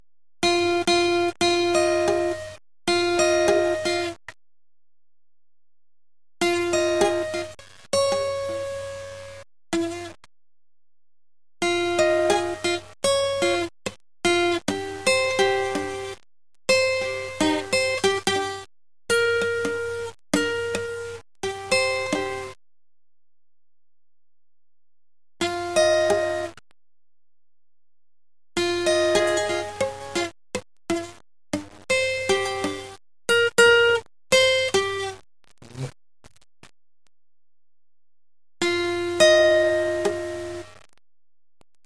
全く音が出ない。